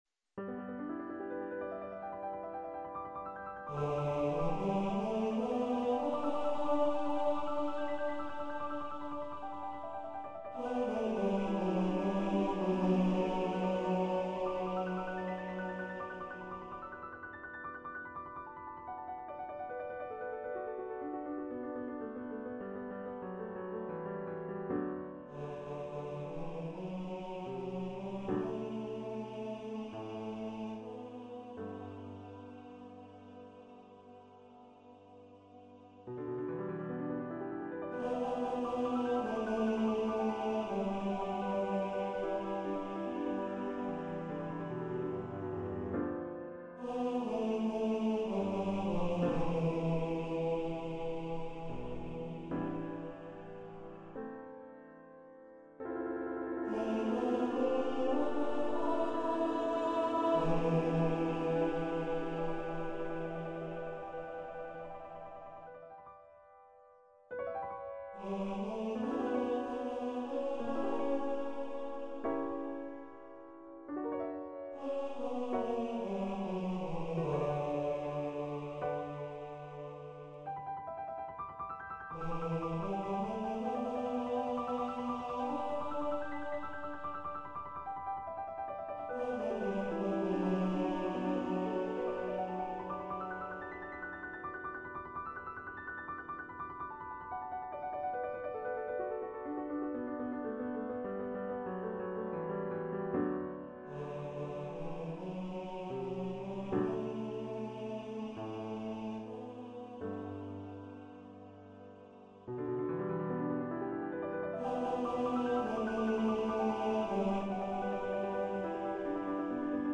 Baritone Voice and Piano
Composer's Demo